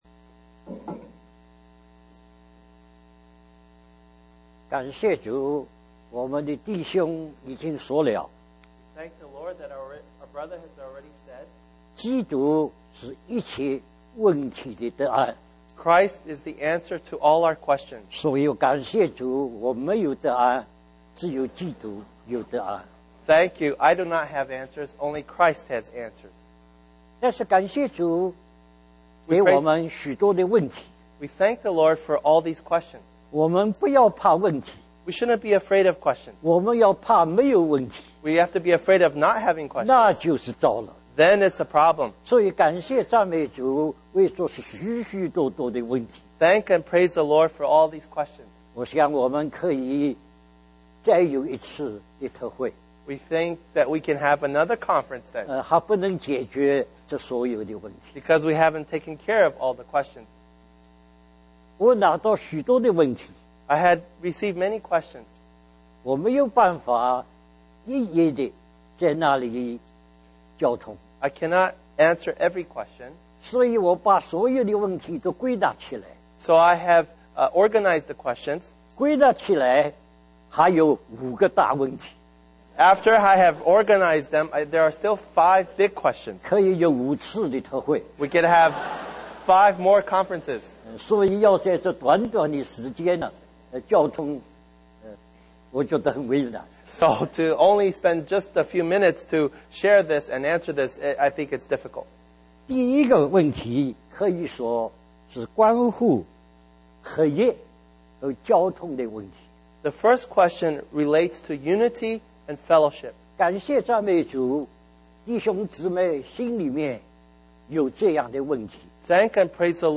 Taiwan, Republic Of China
Question and Answer